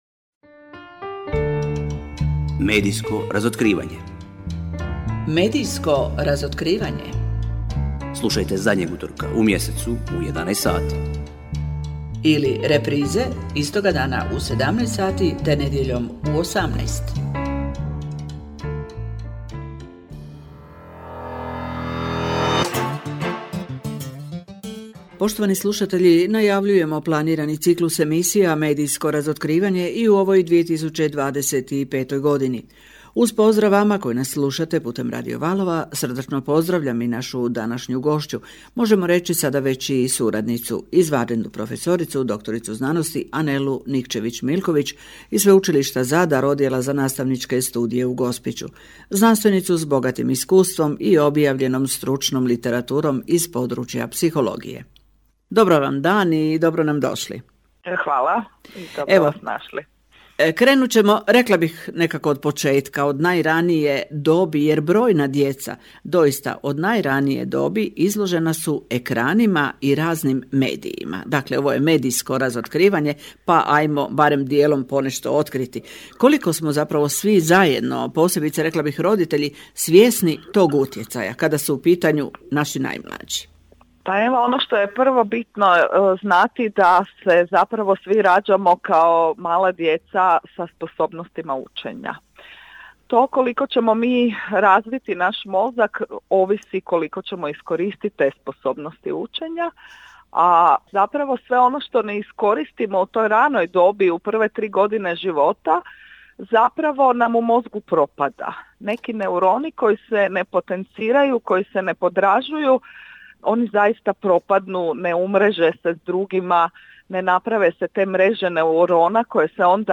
GOSTOVANJE U RADIJSKIM EMISIJAMA